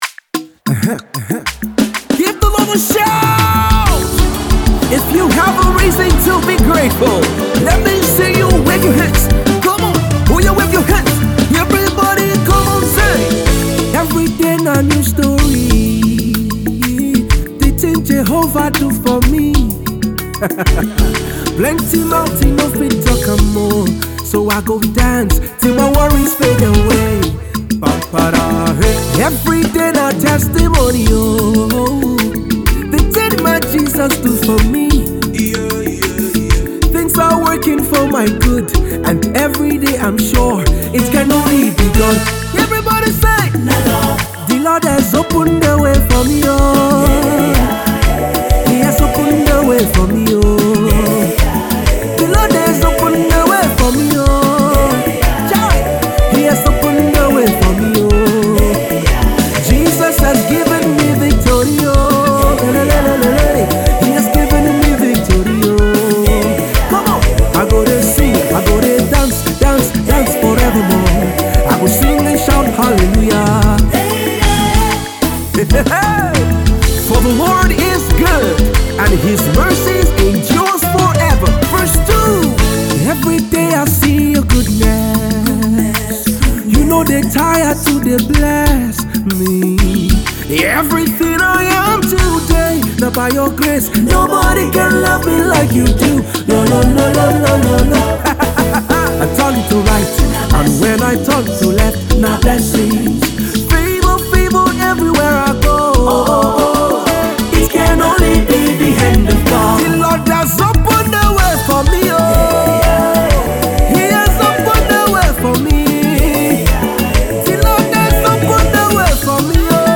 exhilarating and electrifying single